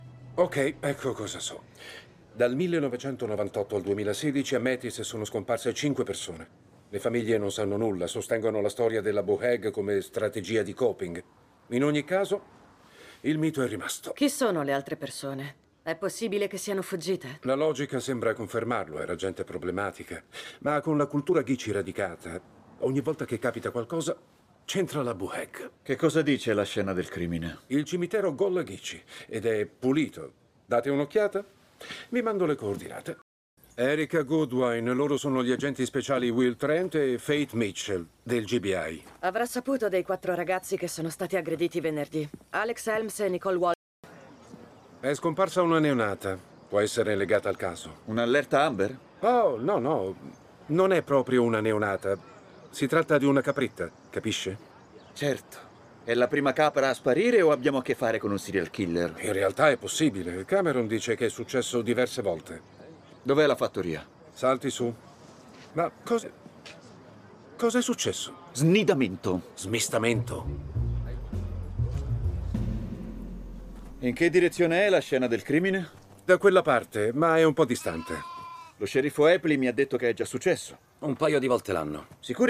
Un’altra voce ancora non identificata è quella di Billy, il “volontario” (così lo definisce IMDb) dello scavo in Montana che si occupa dell’analisi sonar dei resti del raptor.